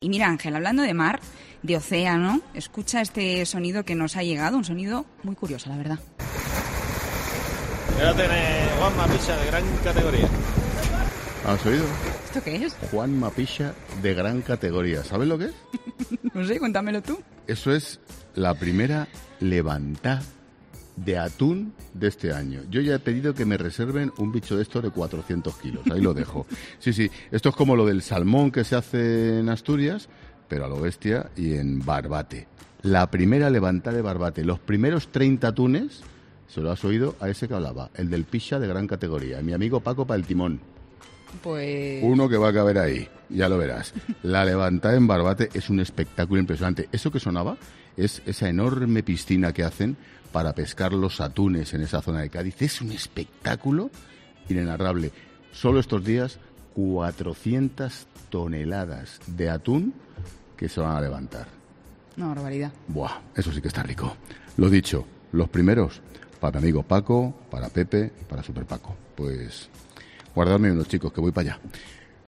Ángel Expósito habla de el atún rojo de El Timón de Roche
El propio Ángel Expósito, en su programa La Linterna, hablaba de cómo El Timón de Roche tendrá un año más uno de los grandes manjares de la gastronomía mundial, como es el atún rojo de almadraba.